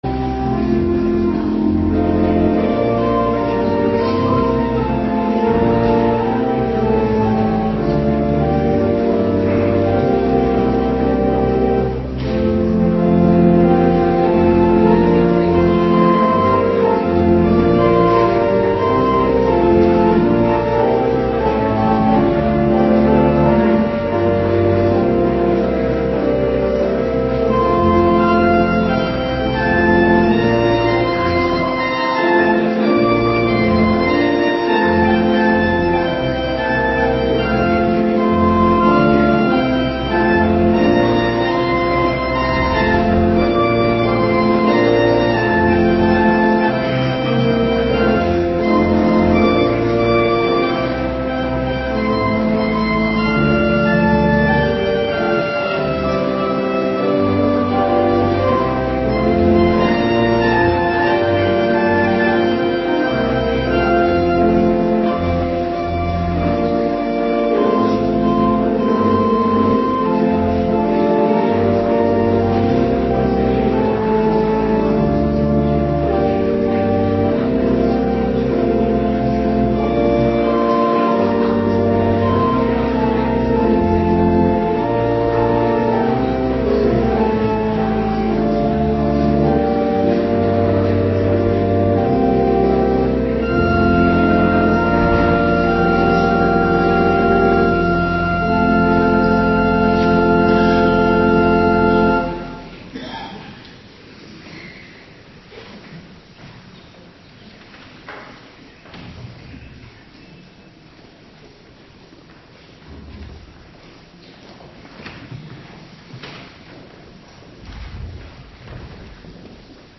Morgendienst 22 februari 2026